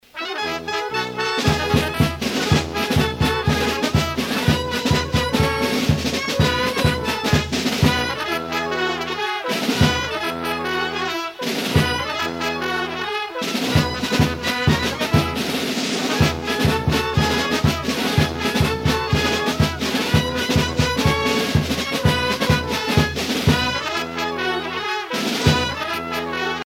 circonstance : carnaval, mardi-gras
Pièce musicale éditée